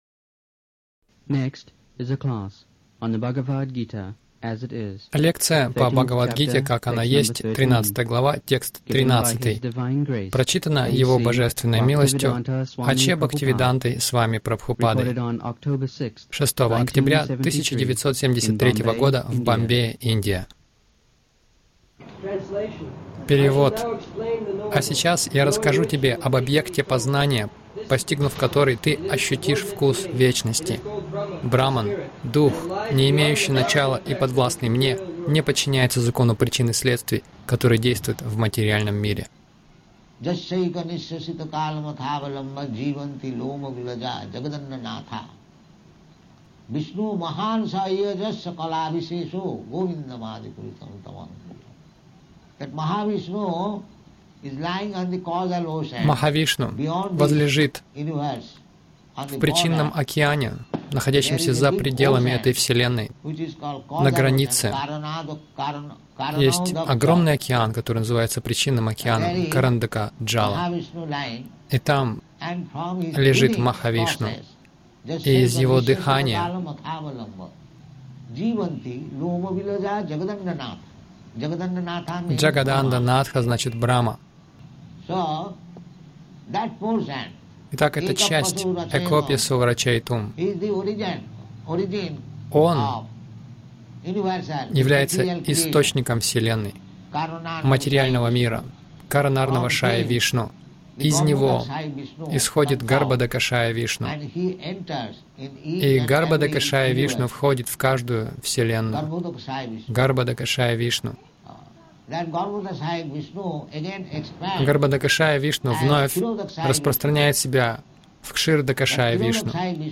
Милость Прабхупады Аудиолекции и книги 06.10.1973 Бхагавад Гита | Бомбей БГ 13.13 — Оковы рождения и смерти Загрузка...